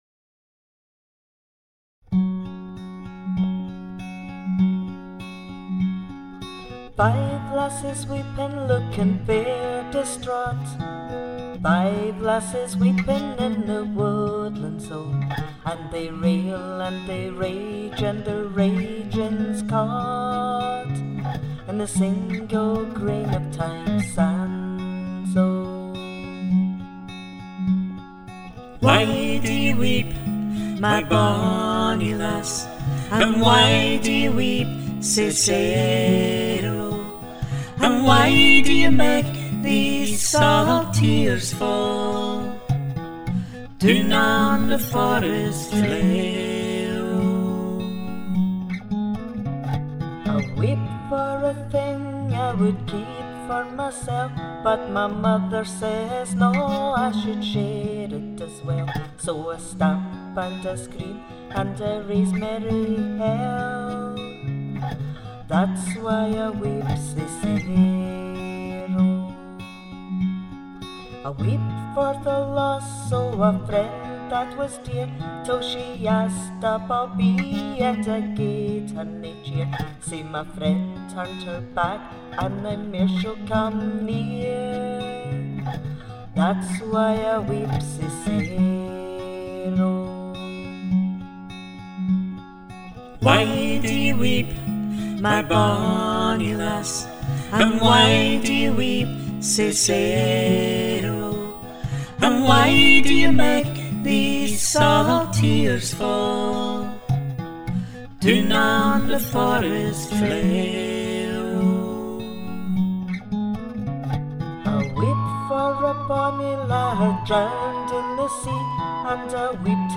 singing the lead verse vocal and the harmony in the chorus
providing the mandocello accompaniment throughout and the harmony whistle duet instrumental (double tracked)